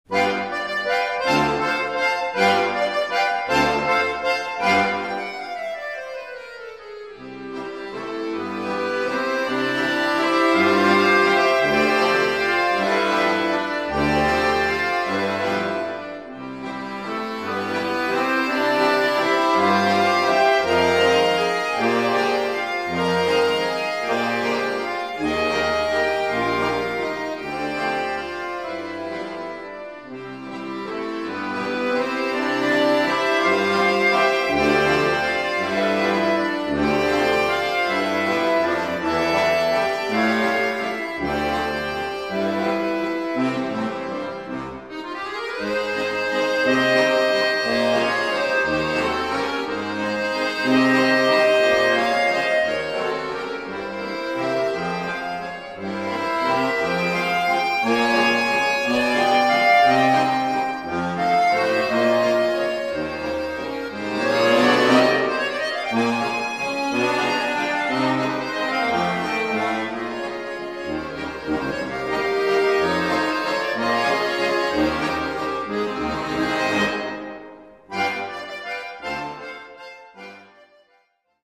Recueil pour Accordéon - Orchestre d'Accordéons